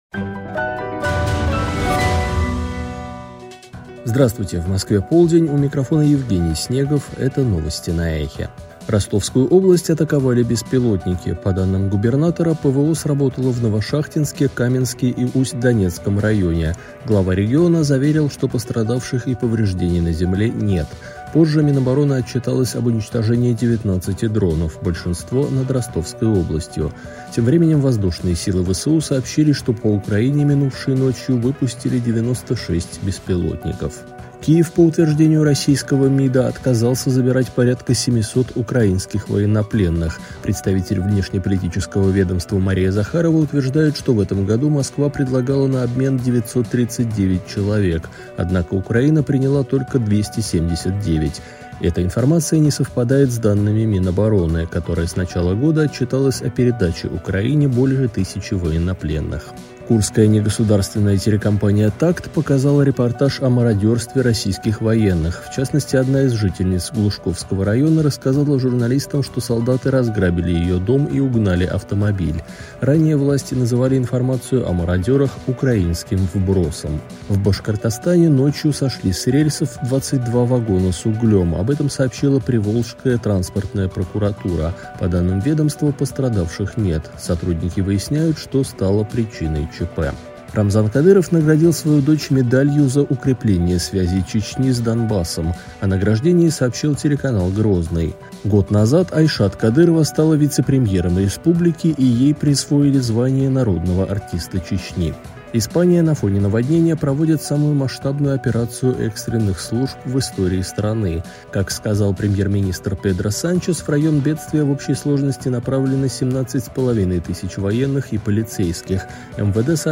Новости 12:00